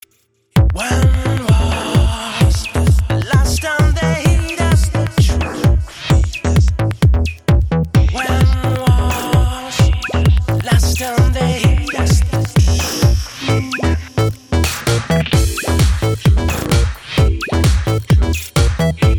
electrohaus/electroclash